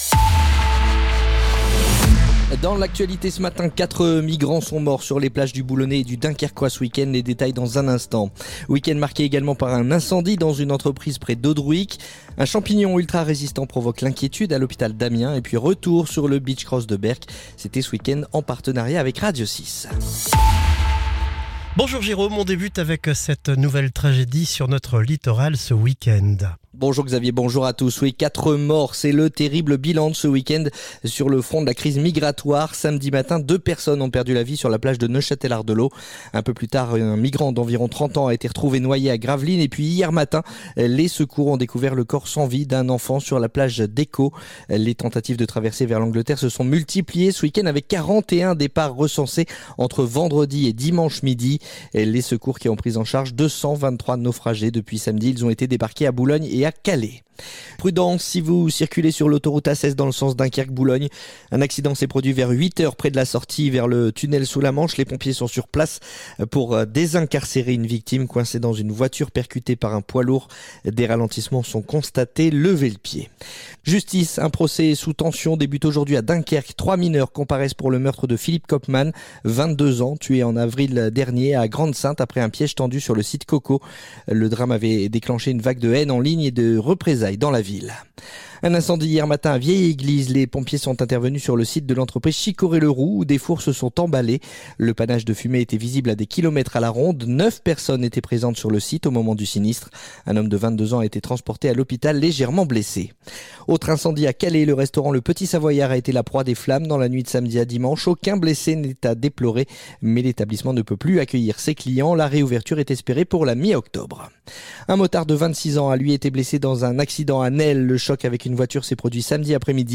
Le journal du lundi 29 septembre